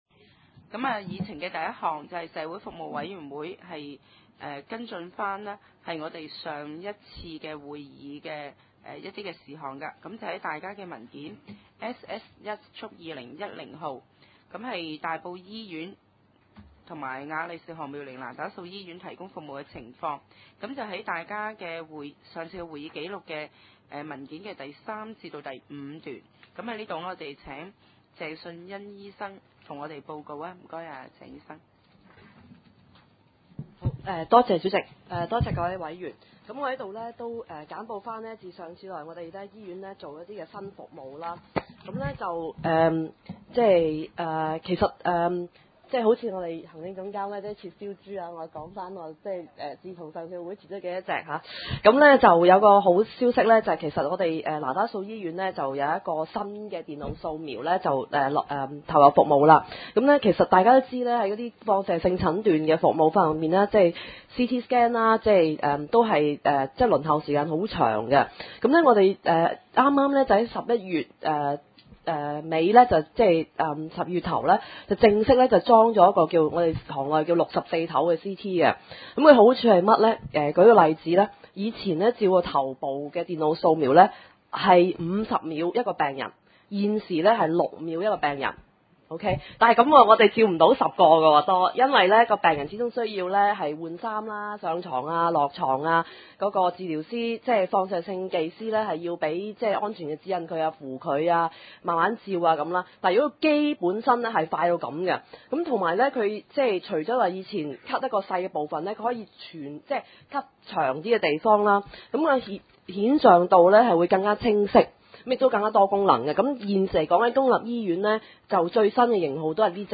大埔區議會 大埔區議會社會服務委員會2010年第一次會議 日期：2010年1月13日 (星期三) 時間：上午9時30分 地點：大埔區議會秘書處會議室 議 程 討論時間 I. 社會服務委員會須跟進的事項 33:46 ( 大埔區議會文件 SS 1/2010 號 ) 39:25 33:27 II.